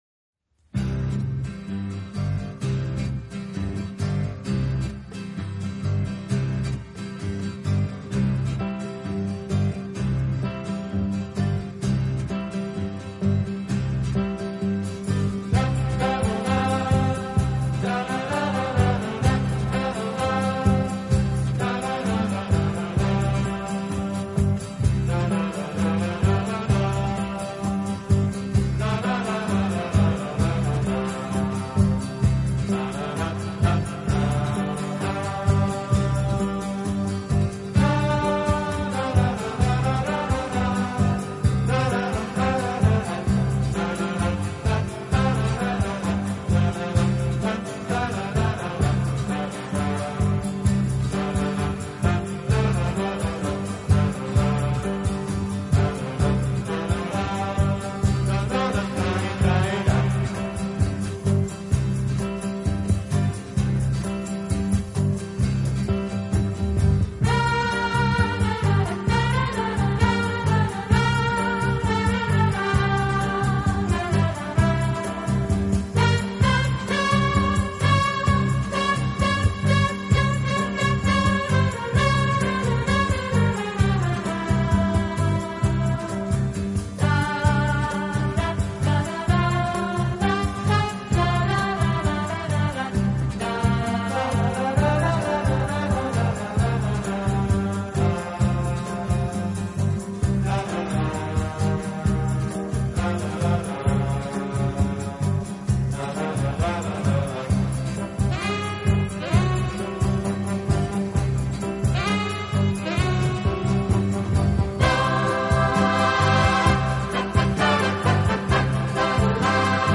【轻音乐专辑】